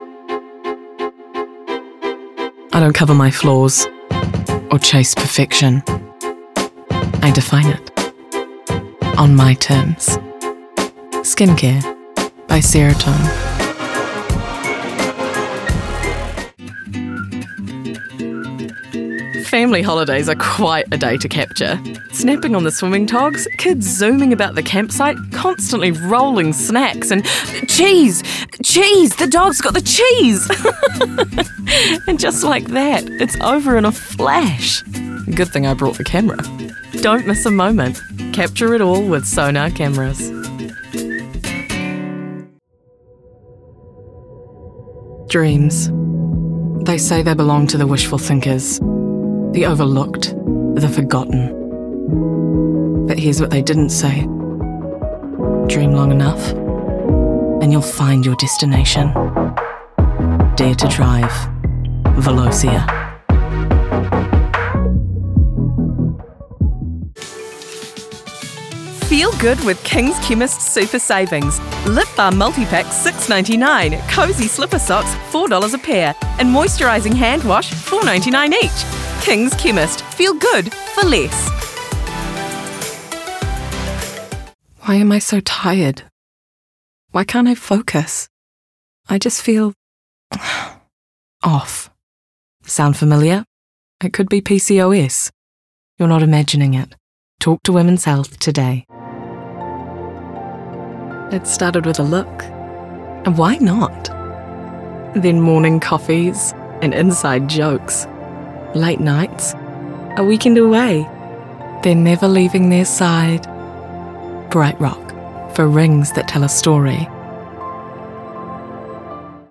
Her sound is very easy on the Ear. She also has an ability to cover a very wide age range and creates fantastically full and rich characters completely different from one to the other perfect for Gaming and Animation.